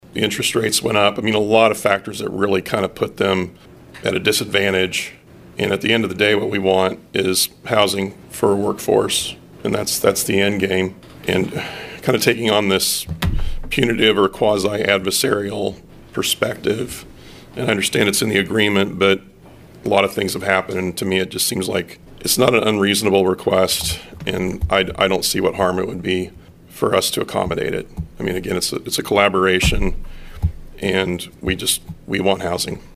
Mayor Greg Lenkiewicz, commented on why he thinks it is a reasonable decision to allow for an extension.